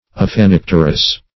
Search Result for " aphanipterous" : The Collaborative International Dictionary of English v.0.48: Aphanipterous \Aph`a*nip"ter*ous\, a. (Zool.) Of or pertaining to the Aphaniptera.